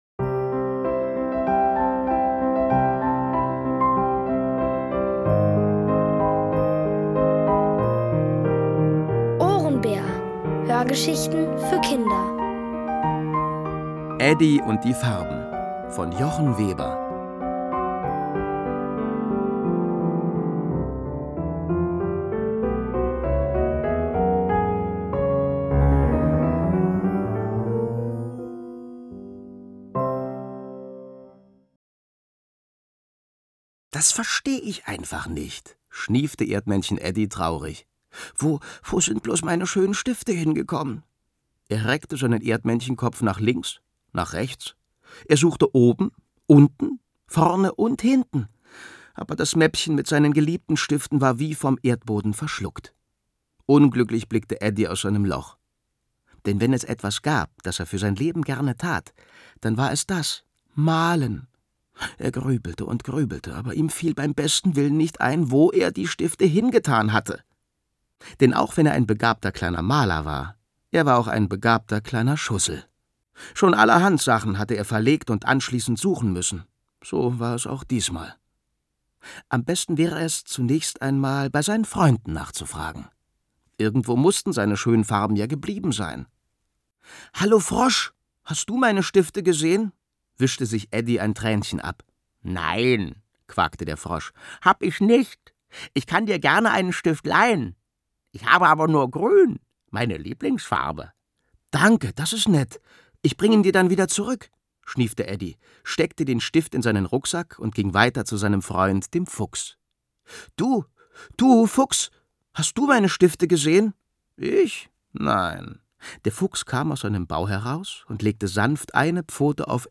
Eddie und die Farben | Die komplette Hörgeschichte!
Von Autoren extra für die Reihe geschrieben und von bekannten Schauspielern gelesen.